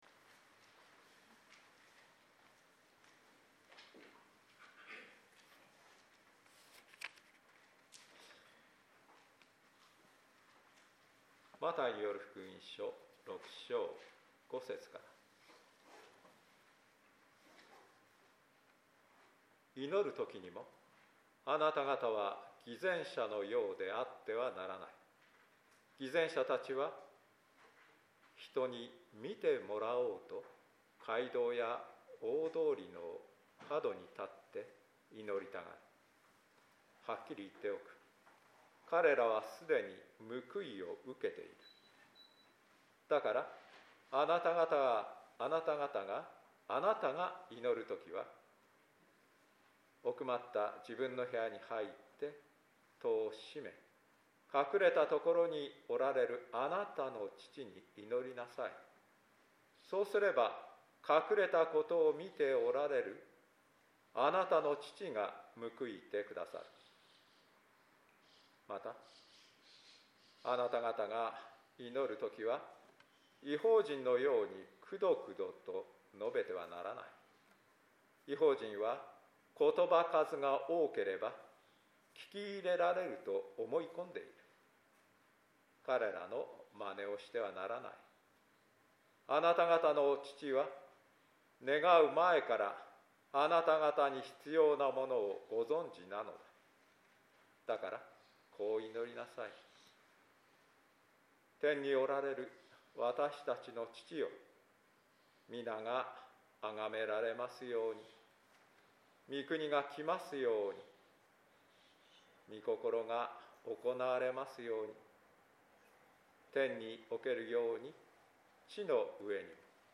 必要なもの（聖霊降臨節第3主日） 2024.6.2説教録音など）